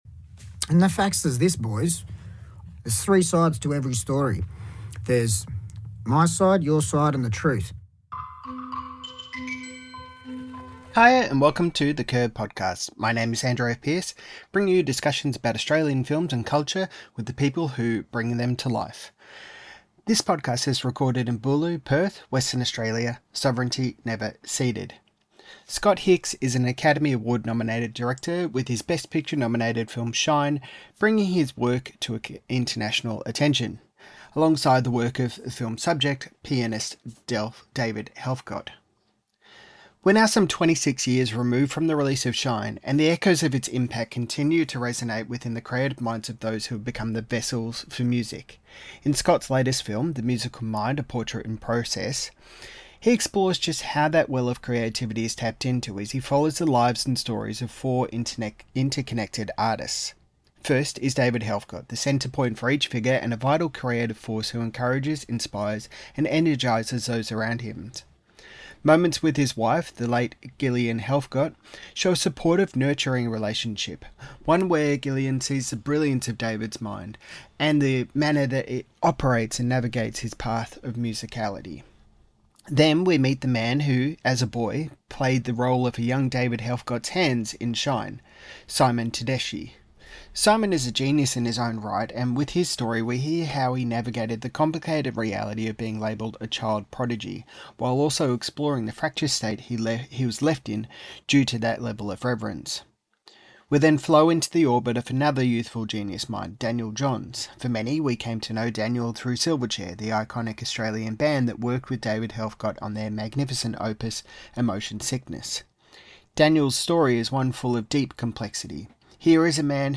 Scott Hicks Talks About Exploring The Musical Mind: A Portrait in Process in This Interview - The Curb